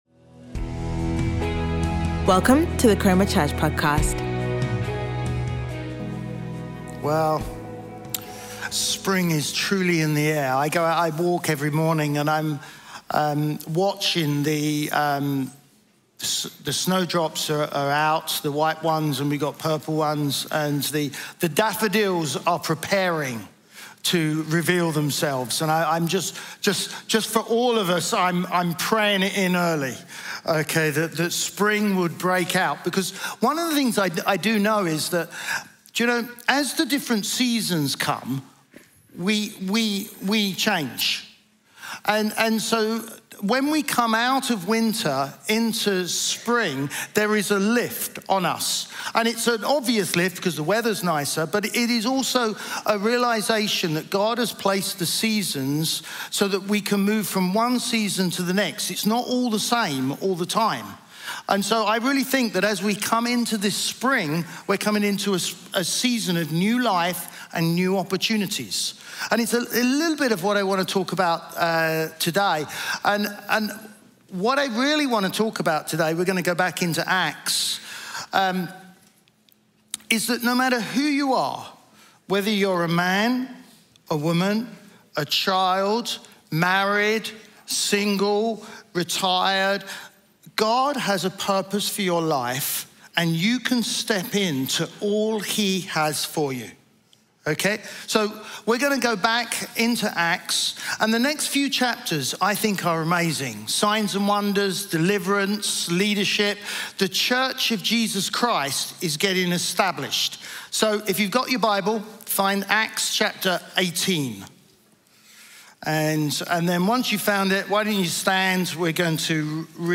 Chroma Church Live Stream
Chroma Church - Sunday Sermon Can Women Lead in the Church?